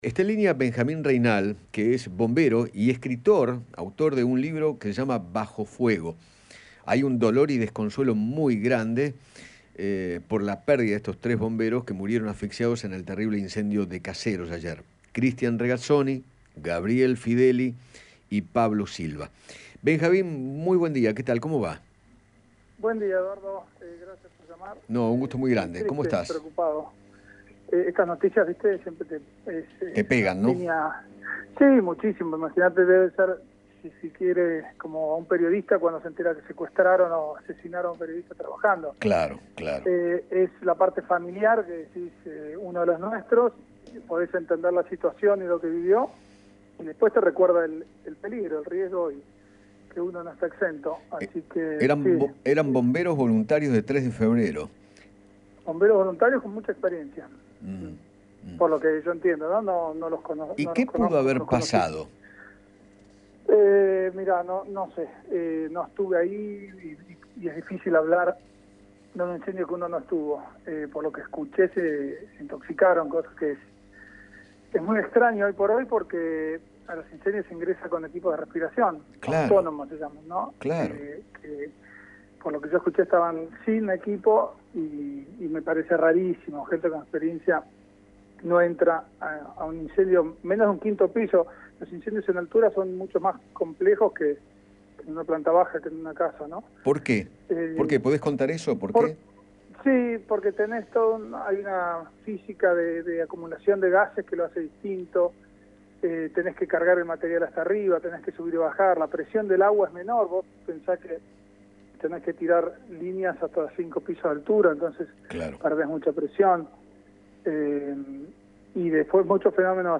conversó con Eduardo Feinmann sobre la muerte de 3 bomberos durante un incendio en Tres de Febrero y se refirió a los riesgos del trabajo.